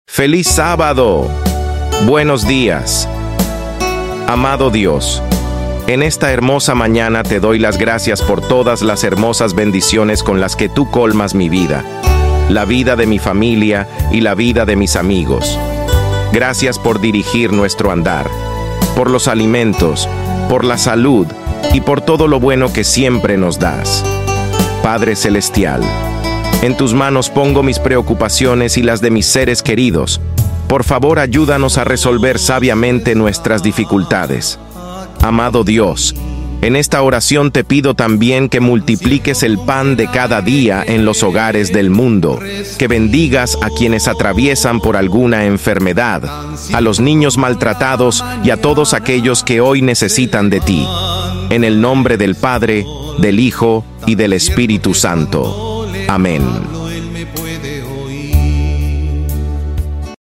ORACIÓN DE SÁBADO.